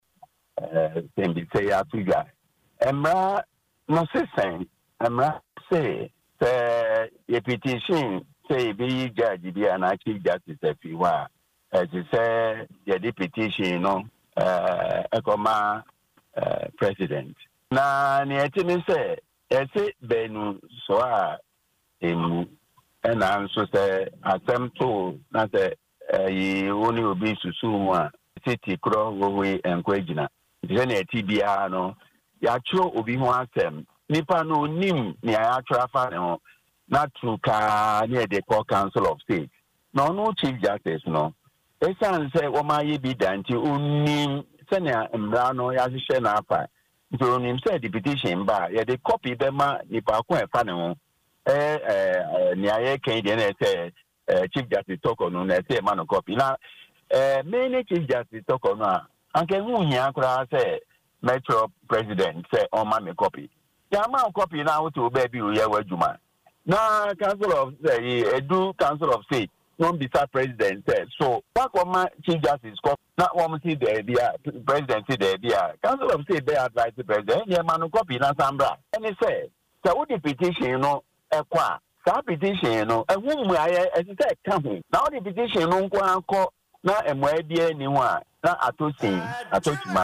Speaking in an interview on Adom FM’s Dwaso Nsem on Thursday